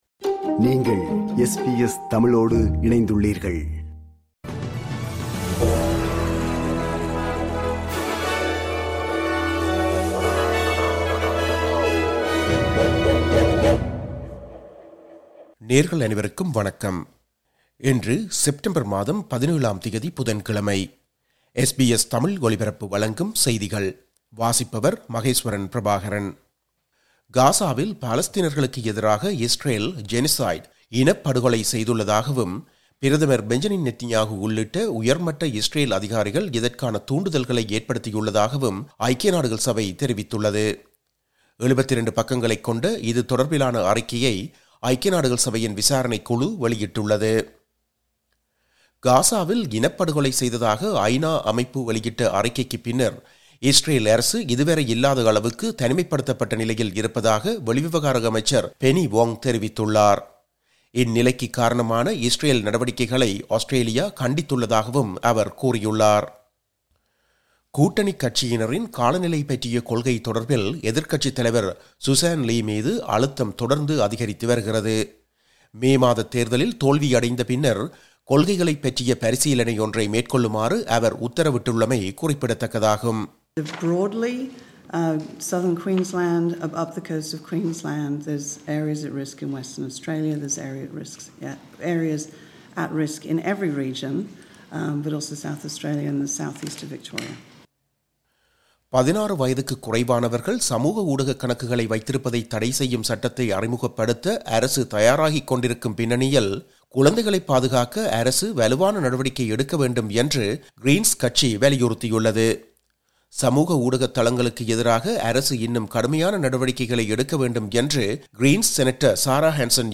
இன்றைய செய்திகள்: 17செப்டம்பர் 2025 புதன்கிழமை
SBS தமிழ் ஒலிபரப்பின் இன்றைய (புதன்கிழமை 17/09/2025) செய்திகள்.